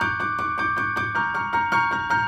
Index of /musicradar/gangster-sting-samples/105bpm Loops
GS_Piano_105-G1.wav